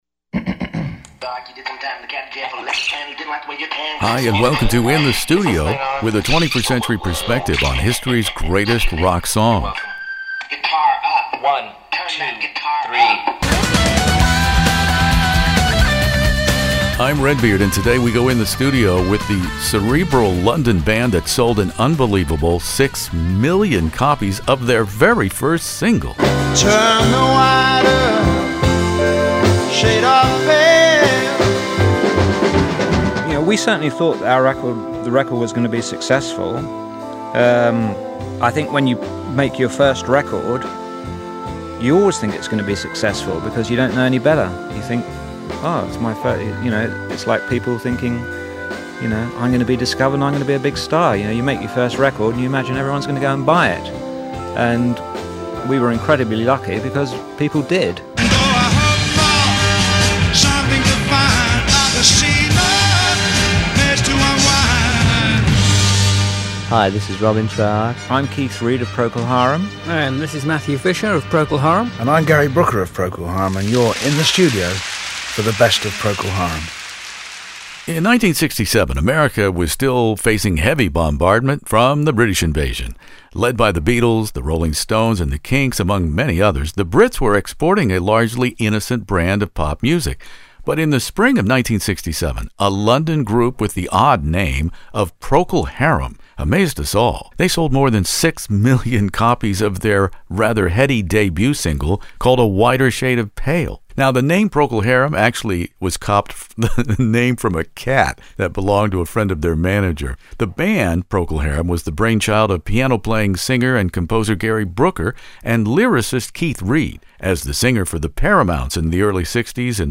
With the recent passings of Procol Harum beloved lead singer/pianist Gary Brooker as well as lyricist Keith Reid, Procol Harum’s biggest-selling album, Live with the ,Edmonton Symphony Orchestra, serves as both a landmark celebration as well as a tribute. Joining me In the Studio in my ultra-rare roundtable to discuss this iconoclastic Art Rock London band were organist Matthew Fisher; the reclusive lyricist Keith Reid, the fabulous guitarist Robin Trower, who recorded on the first three Procol Harum studio albums; and my archival interview with the late Gary Brooker himself.